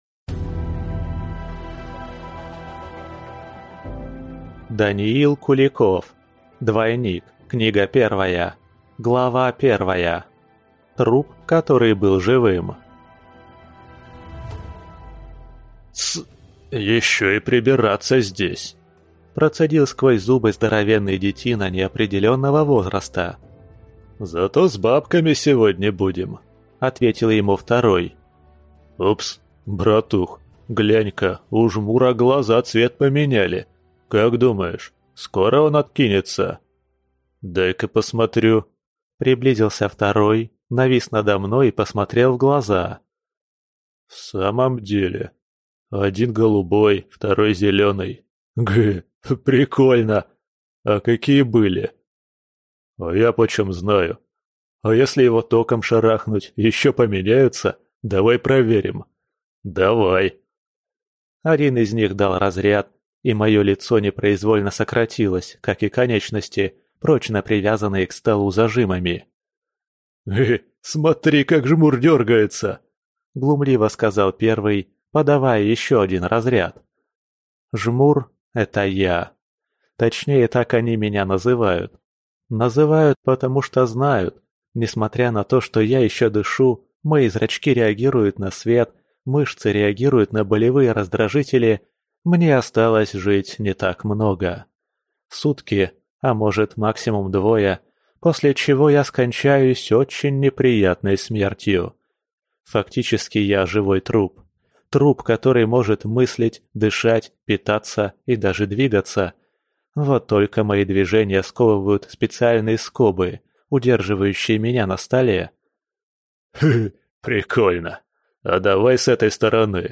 Аудиокнига Двойник. Книга 1 | Библиотека аудиокниг